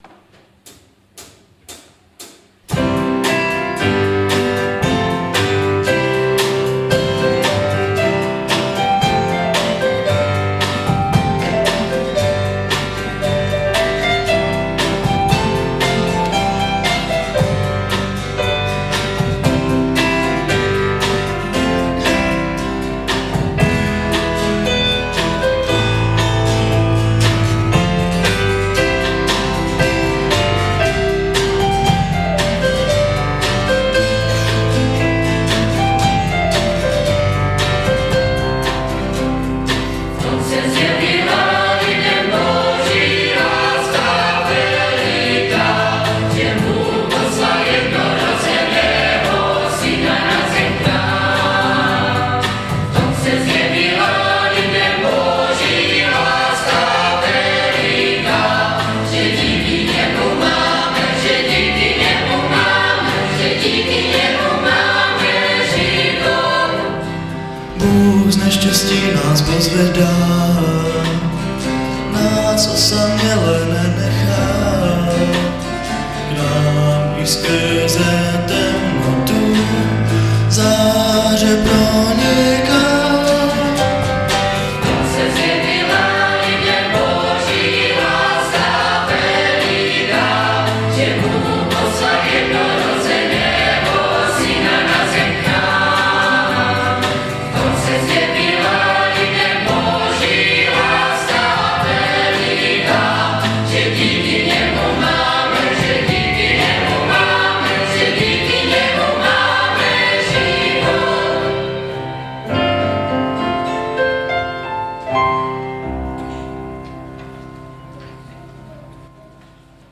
01-javornicky-sbor-_-vanocni-kantata_32.mp3